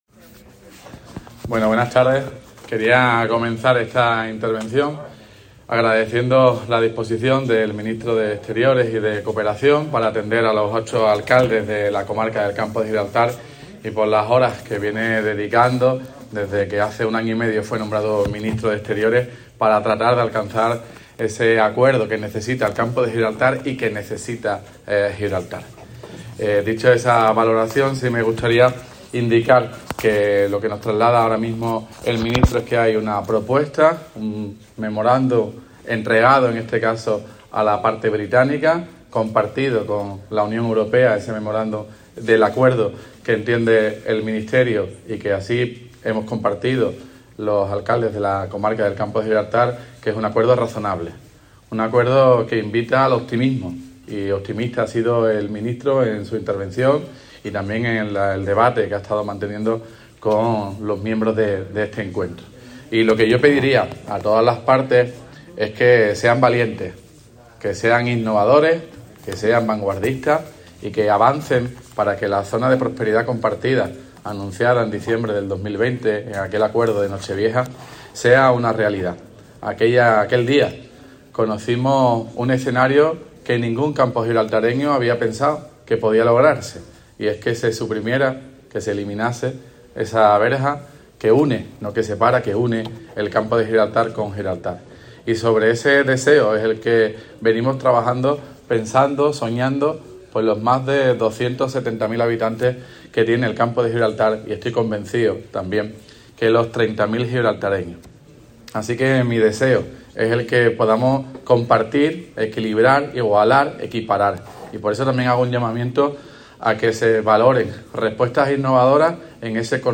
REUNIÓN_MINISTRO_ALBARES_TOTAL_ALCALDE.mp3